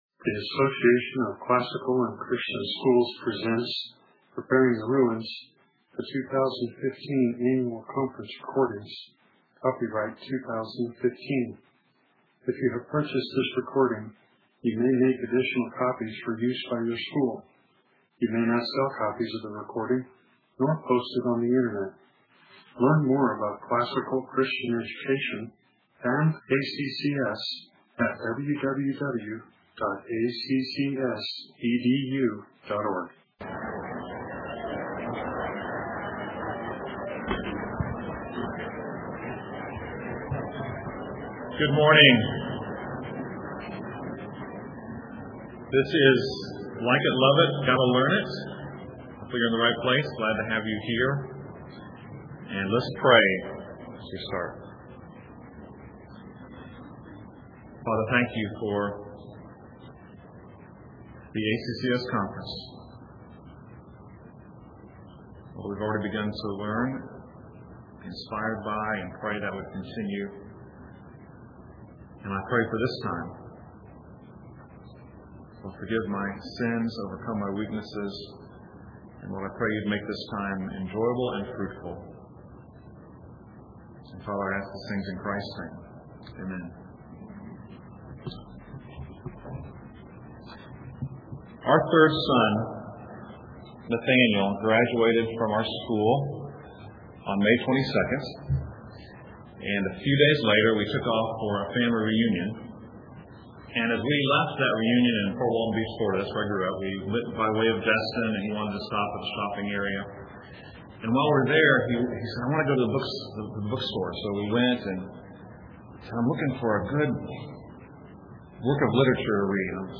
2015 Workshop Talk | 1:04:03 | All Grade Levels, General Classroom